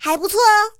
T-60补给语音.OGG